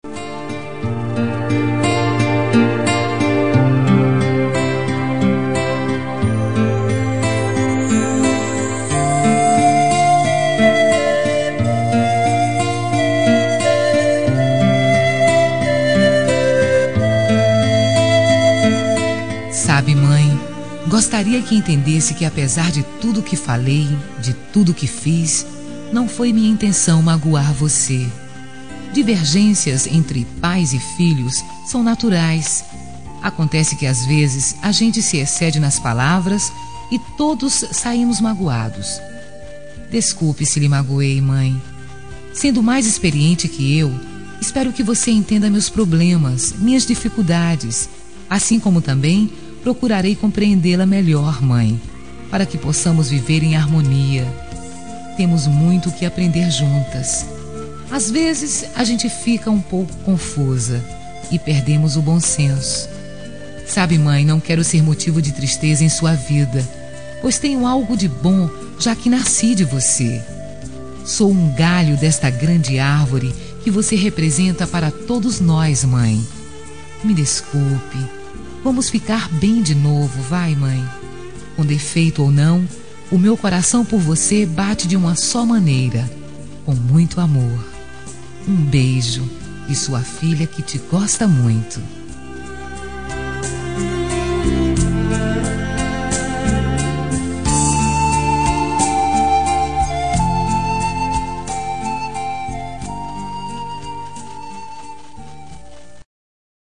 Reconciliação Familiar – Voz Feminina – Cód: 088721 – Mãe